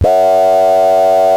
BUCHLA.F#3 -.wav